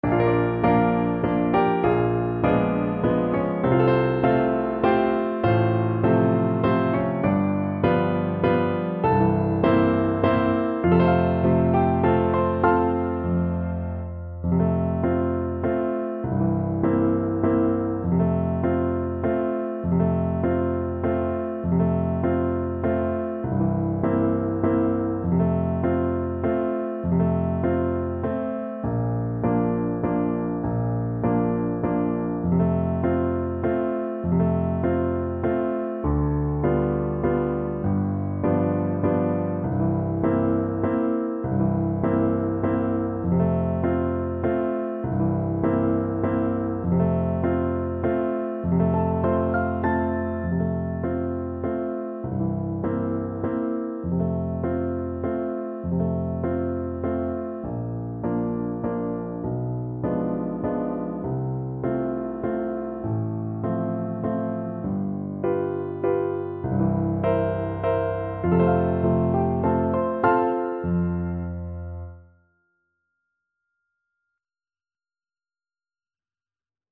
~ = 100 Slowly and dreamily
3/4 (View more 3/4 Music)